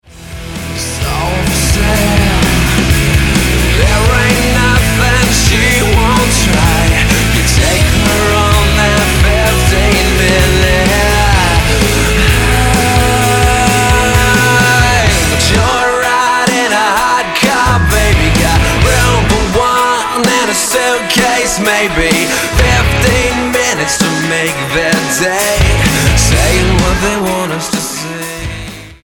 STYLE: Rock